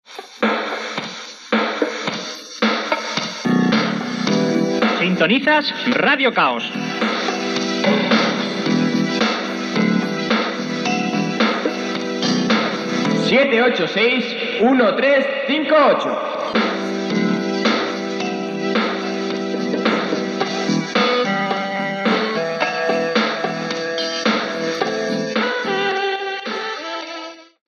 Identificació i telèfon de l'emissora
Banda FM